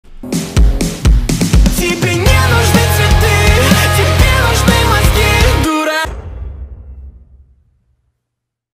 • Качество: 321 kbps, Stereo